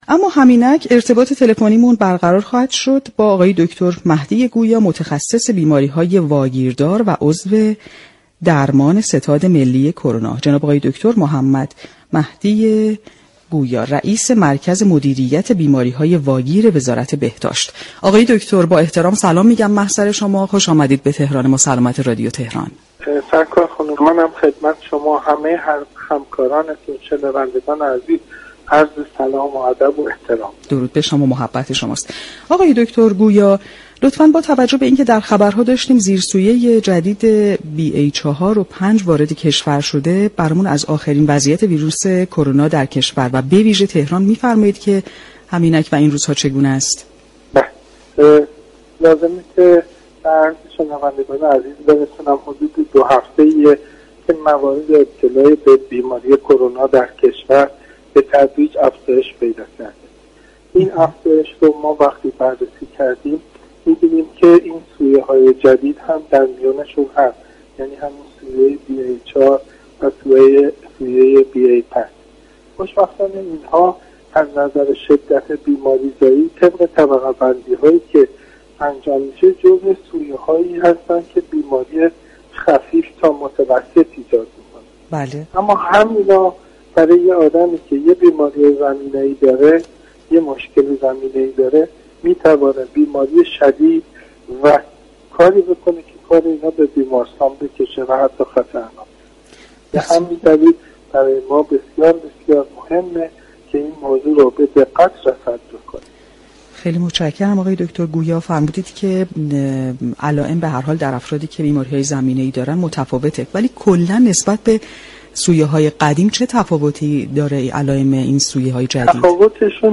به گزارش پایگاه اطلاع رسانی رادیو تهران، محمدمهدی گویا رئیس مركز مدیریت بیماری‌های وزارت بهداشت در گفت و گو با برنامه تهران ما سلامت رادیو تهران در روز شنبه 18 تیرماه درباره شیوع مجدد بیماری كرونا در كشور با اشاره به اینكه دو هفته است كه آمار مبتلایان این بیماری در حال افزایش است گفت: در بین بیماران زیر سویه‌های BA4 و BA5 هم دیده می‌شود.